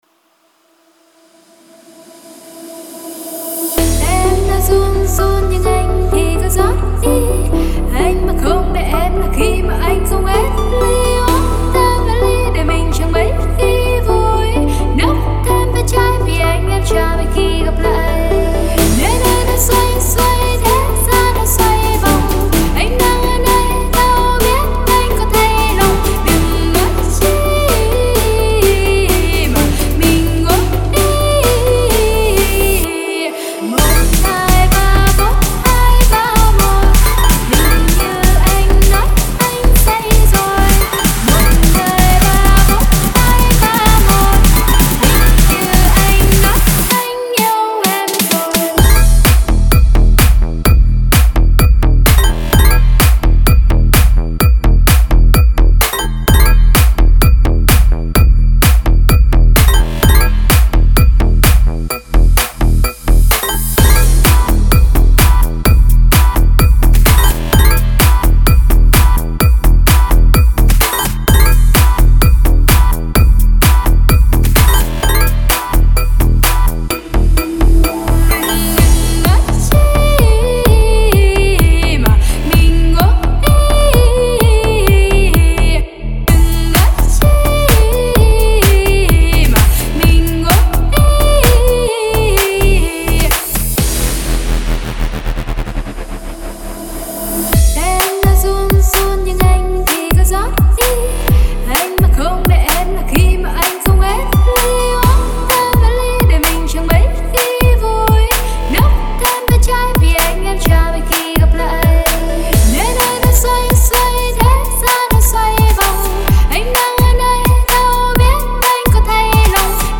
ژانر: رپ & آر اند بی & پاپ